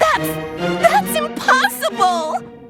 Worms speechbanks
AMAZING.WAV